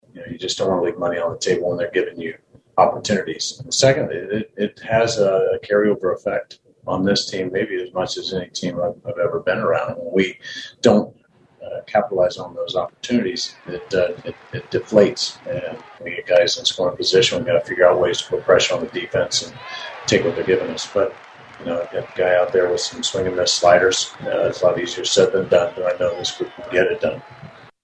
Manager Mike Matheny says they need to take advantage of their opportunities.